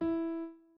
01_院长房间_钢琴_09.wav